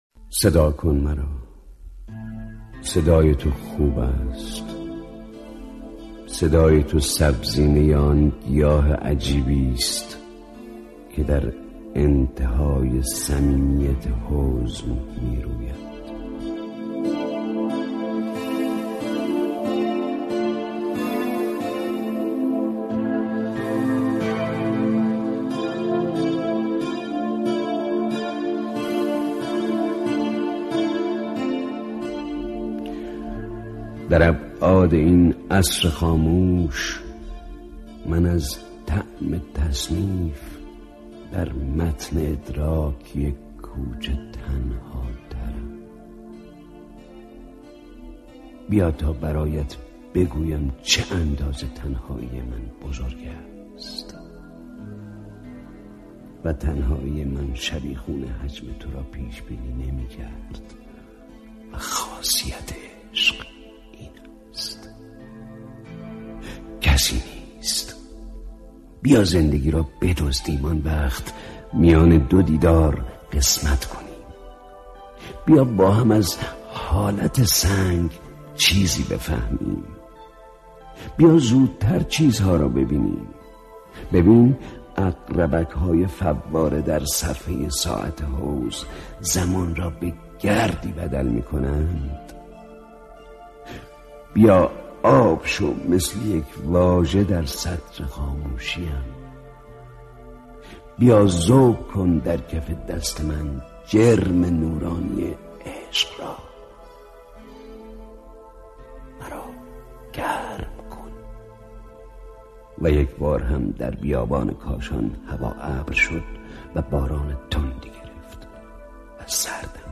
همسفران (با صدای خسرو شکیبایی)
صوت همسفران (با صدای خسرو شکیبایی) از شاعر سهراب سپهری در نشریه وزن دنیا